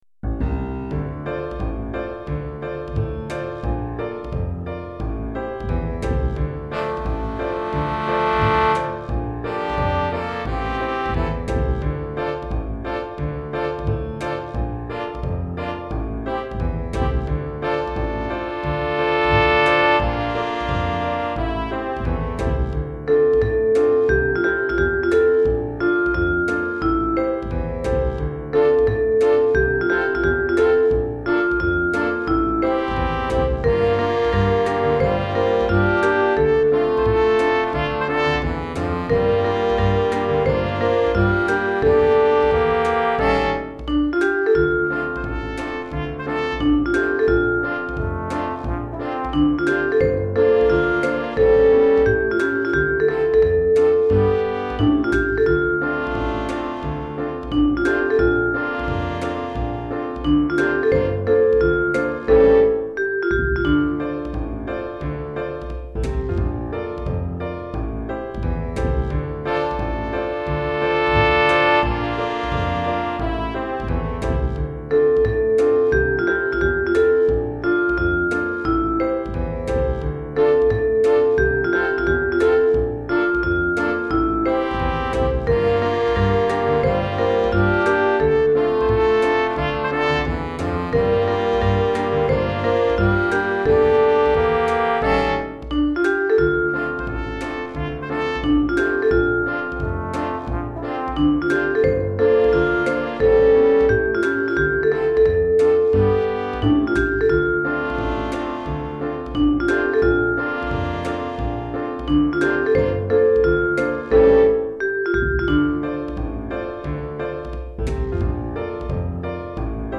Chorale d'Enfants (10 à 12 ans), Trompette